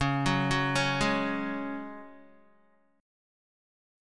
C#sus4 chord